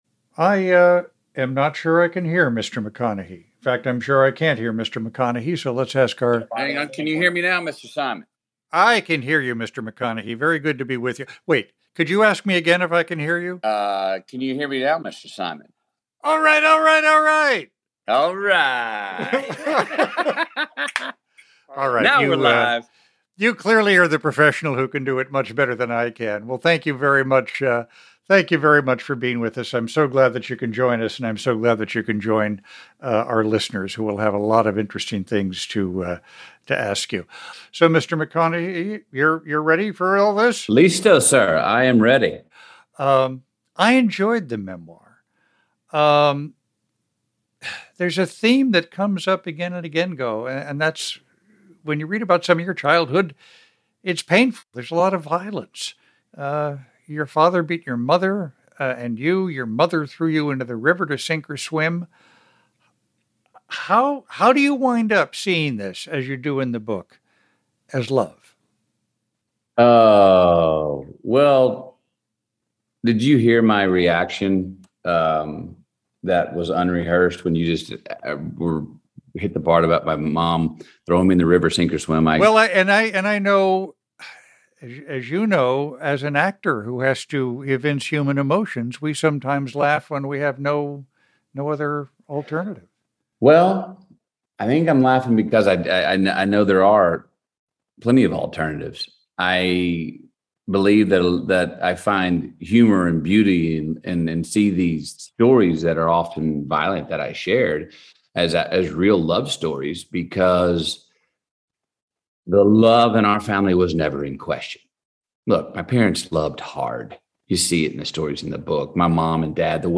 In a Twitter Spaces chat hosted by NPR, the actor discussed his potential run for Texas governor, where he fits — or doesn't — on the political party spectrum, abortion, Amazon unions and more.